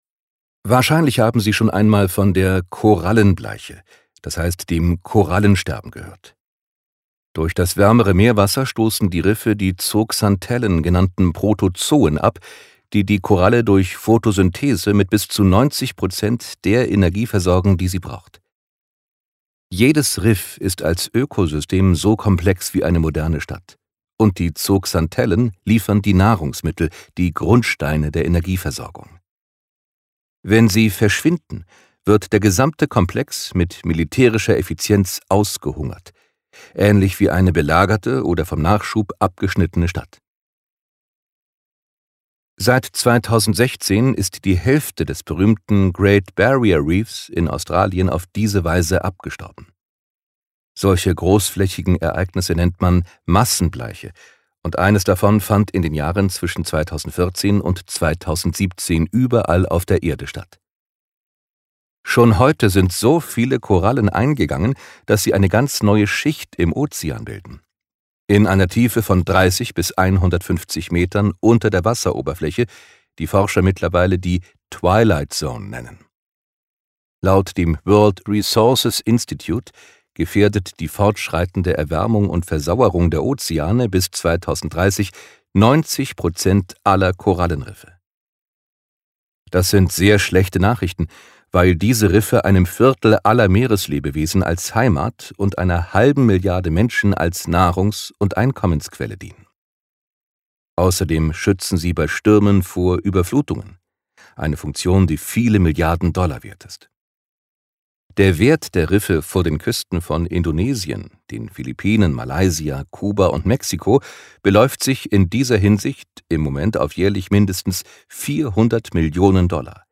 Genre: Lesung.